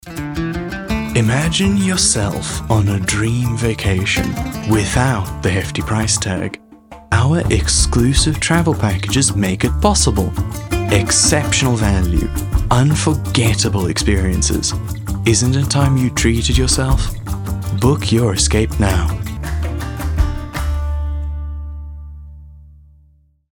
animation, articulate, authoritative, character, Deep
Medium Sell- Dream Vacation